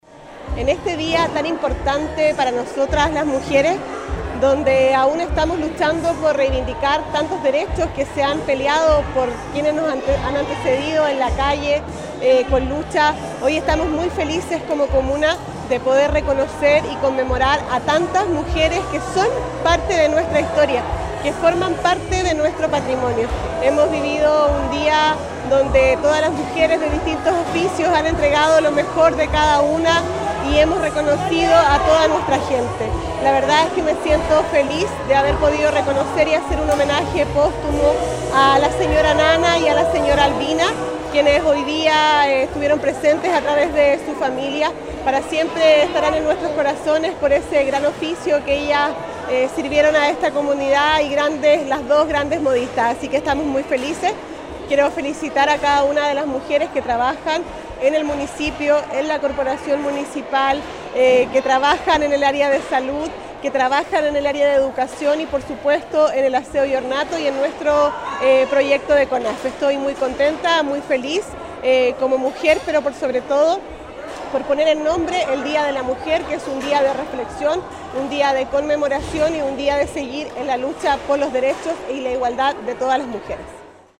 Esto se realizó en una emotiva y concurrida ceremonia la tarde de este viernes 8 de marzo en el gimnasio de la población Bordemar, la cual fue en encabezada por la alcaldesa Javiera Yáñez, junto al Concejo Municipal; la presencia del Delegado Presidencial de la Provincia de Chiloé, Marcelo Malagueño; el Consejero Regional Nelson Águila e invitados especiales y familiares de las mujeres que fueron destacadas este 2024.
Esta actividad fue organizada por el municipio curacano a través de su Oficina de la Mujer y Equidad de Género, como lo manifestó la jefa comunal, quien detalló que la fecha fue un momento de reflexión:
ALCALDESA-8M-1.mp3